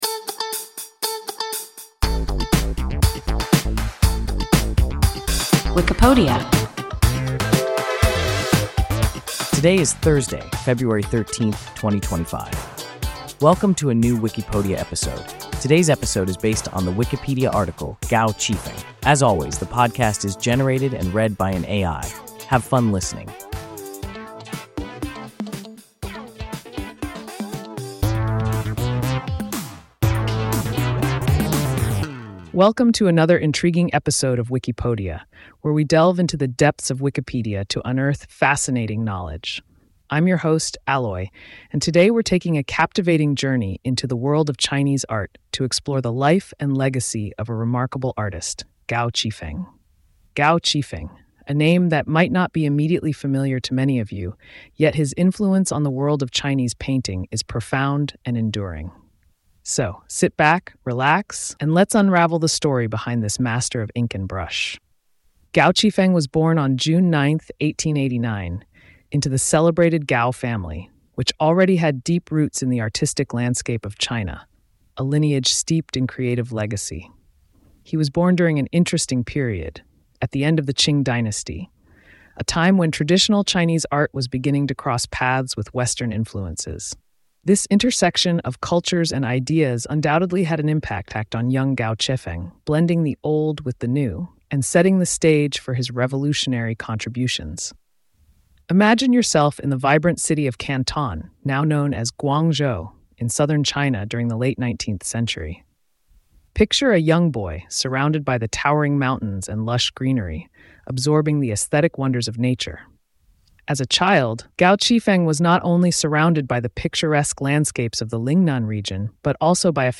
Gao Qifeng – WIKIPODIA – ein KI Podcast